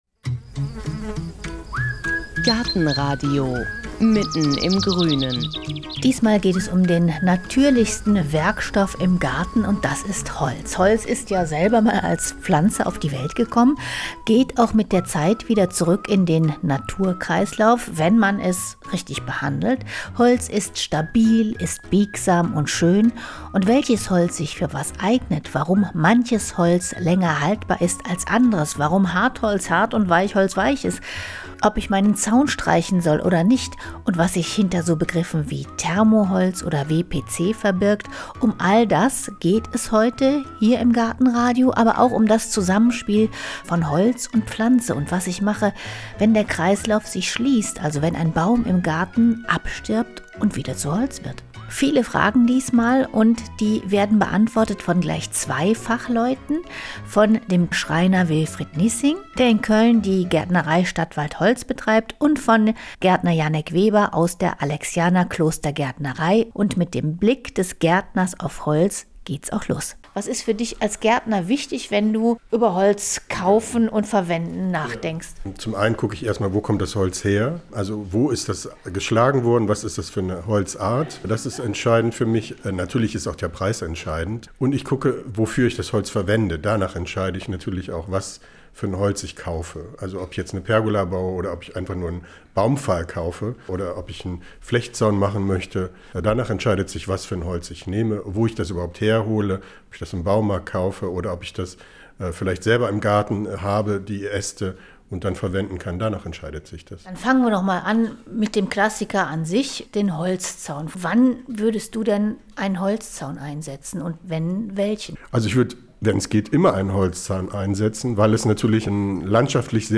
Wie nutze ich einen abgestorbenen Baum und seine Wurzeln? All diese Fragen beantworten diesmal ein Gärtner und ein Schreiner.